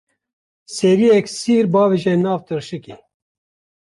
/siːɾ/